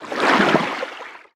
Sfx_creature_titanholefish_swim_06.ogg